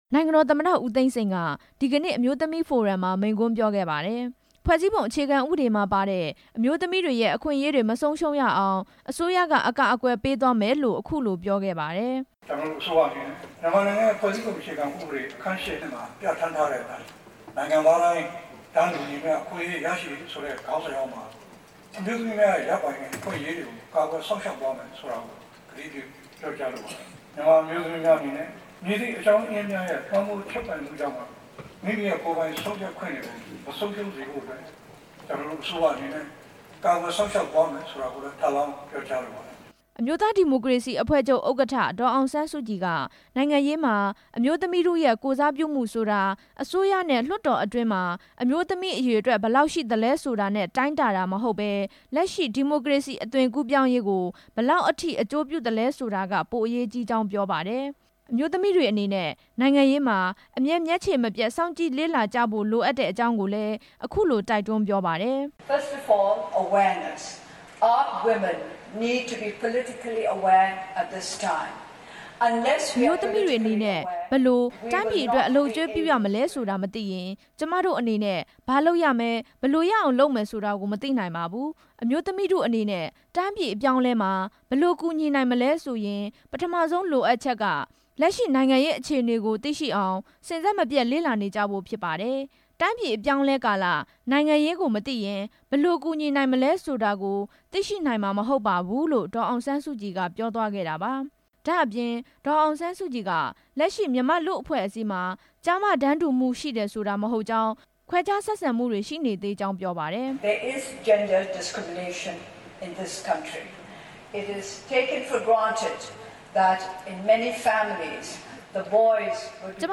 ဒီနေ့ နေပြည်တော်မှာ ကျင်းပတဲ့ ကမ္ဘာလုံးဆိုင်ရာအမျိုးသမီးဖိုရမ်မှာ နိုင်ငံတော်သမ္မတ ဦးသိန်းစိန်နဲ့ အမျိုးသားဒီမိုကရေစီအဖွဲ့ချုပ် ဥက္ကဌ ဒေါ်အောင်ဆန်းစုကြည် တို့က တက်ရောက်မိန့်ခွန်း ပြောကြားခဲ့ပါတယ်။